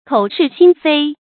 注音：ㄎㄡˇ ㄕㄧˋ ㄒㄧㄣ ㄈㄟ
口是心非的讀法